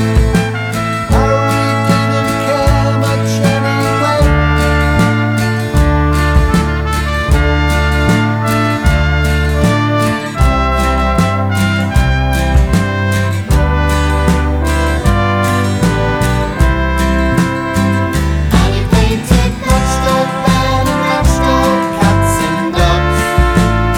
For Solo Male Pop (1970s) 4:06 Buy £1.50